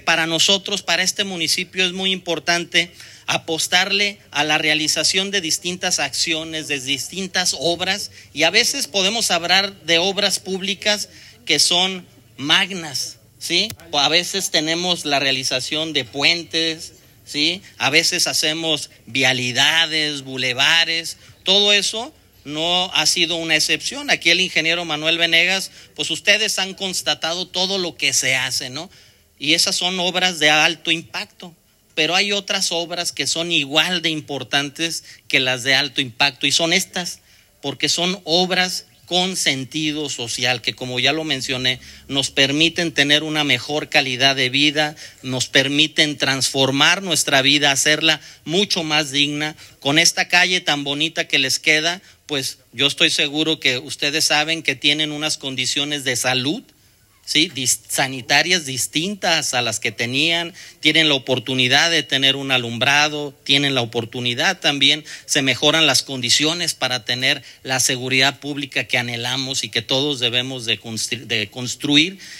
Rodolfo Gómez Cervantes, Presidente Municipal Interino